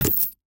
UIMvmt_Menu Medieval Chains 01.wav